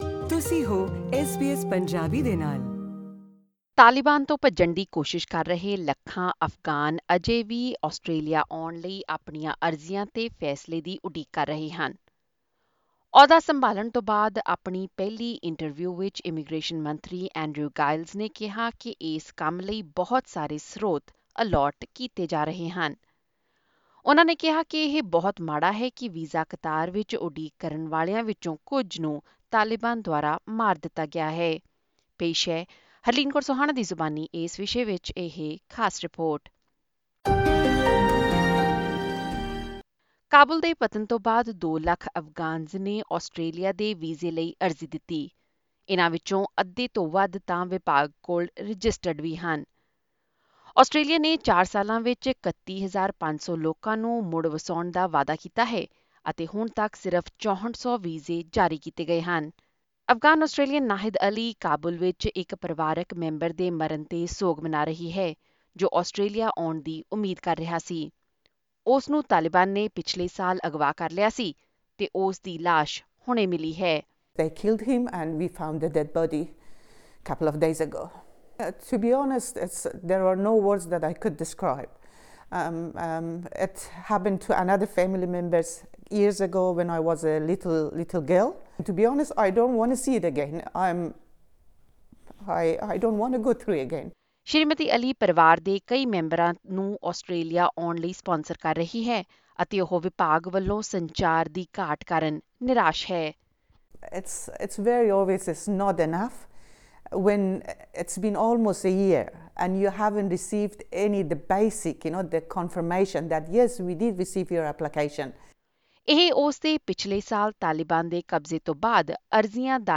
Hundreds of thousands of Afghans trying to flee the Taliban are still waiting for their applications to come to Australia to be considered. In one of his first interviews since taking the position, Immigration Minister Andrew Giles says enormous resources are being allocated to the task.